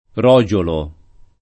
vai all'elenco alfabetico delle voci ingrandisci il carattere 100% rimpicciolisci il carattere stampa invia tramite posta elettronica codividi su Facebook rogiolo [ r 0J olo ] s. m. (zool.) — uno dei sinon. tosc. di «ramarro» — sim. il top. m. R. (Tosc.)